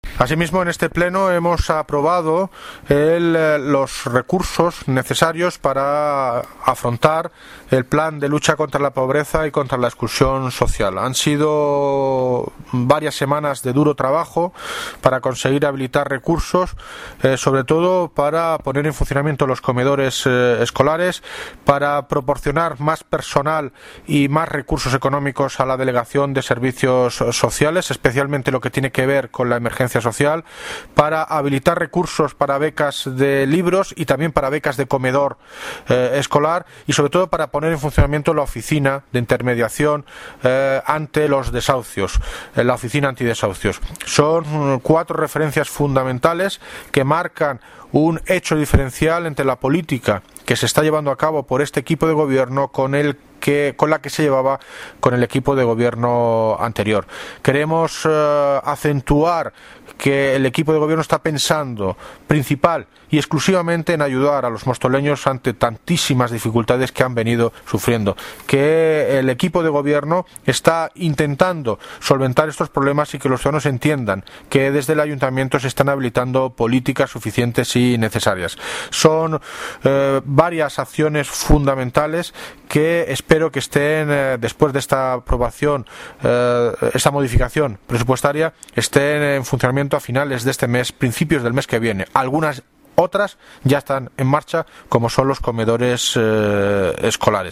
Audio de David Lucas, Alcalde de Móstoles